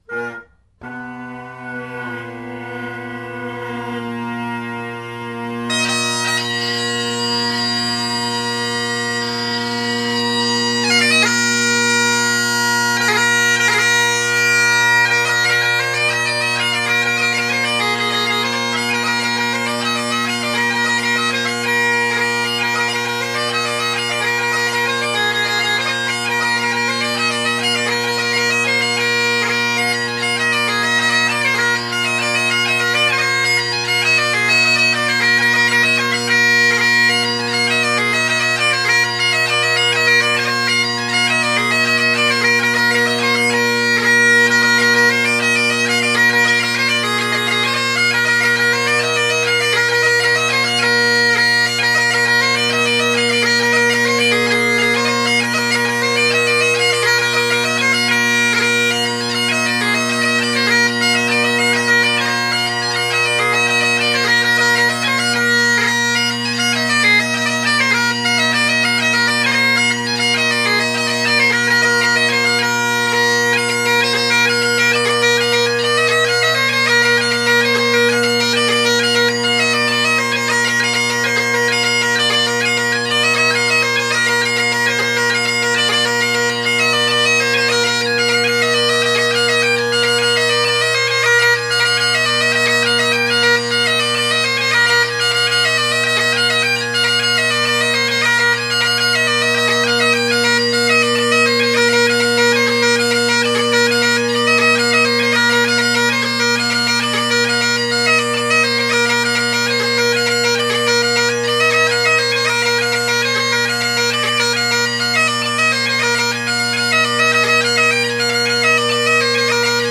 I find the tenor reeds to move with the chanter very well, the bass less so and therefore requiring more tuning, but they all settle in nice. They are very bold reeds. I get quite a nice lovely ring off the tenors, with maybe a bit of edge to them.
Oh yeah, old style Kron band chanter with Gilmour reed.